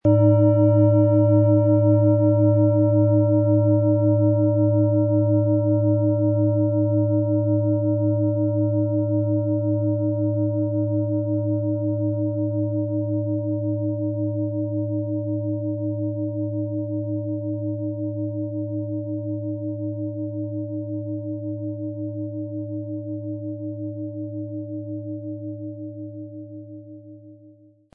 Planetenton 1
Sie sehen und hören eine von Hand gefertigt Neptun Klangschale.
Ein die Schale gut klingend lassender Schlegel liegt kostenfrei bei, er lässt die Planetenklangschale Neptun harmonisch und angenehm ertönen.
MaterialBronze